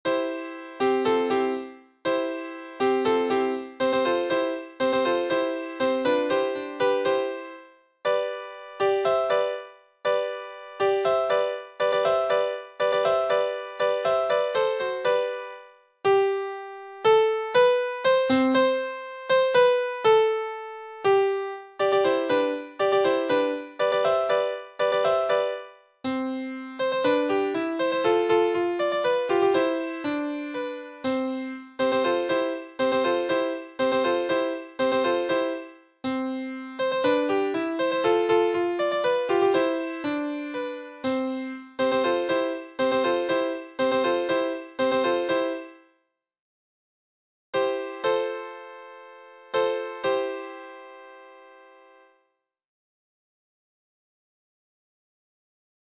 Interpretación a piano de la pieza musical perteneciente a Haendel denominada "Aleluya"
piano